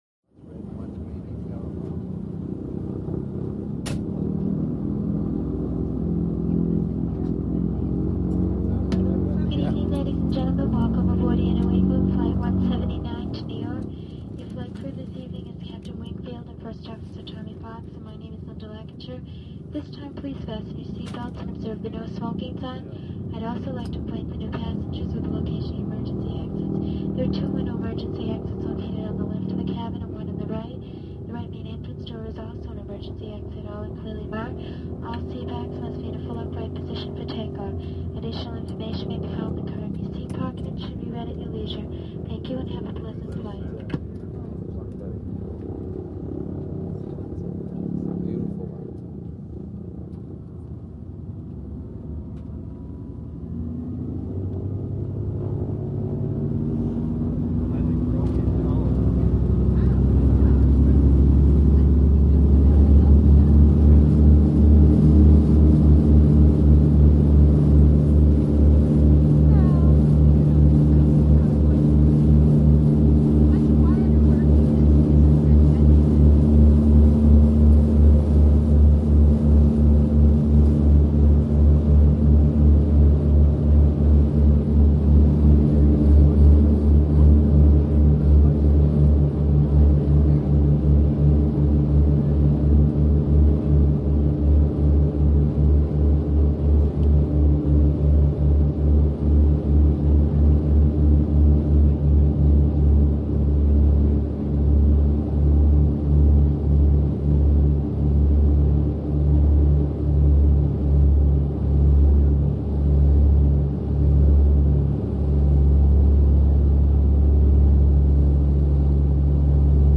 AIRCRAFT PROP PROP: INT: Passenger walla, P.A. announcements, taxi, take off, ascending, steady flight. Air New England.